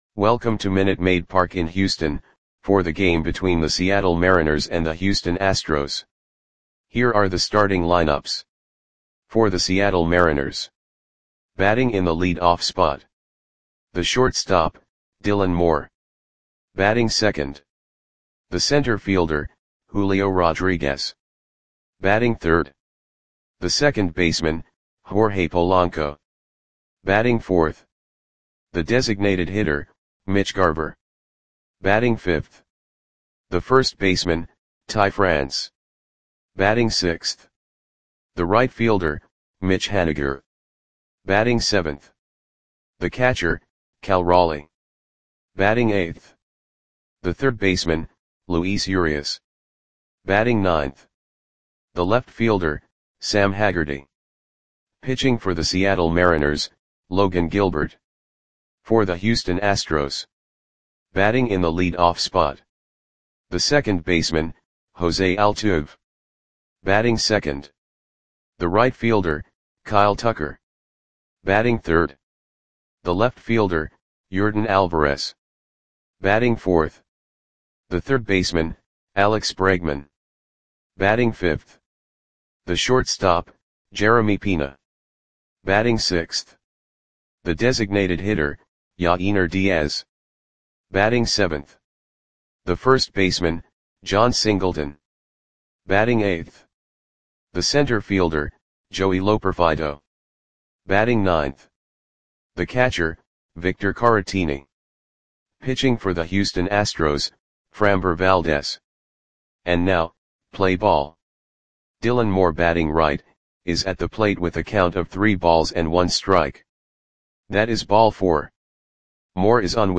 Audio Play-by-Play for Houston Astros on May 4, 2024
Click the button below to listen to the audio play-by-play.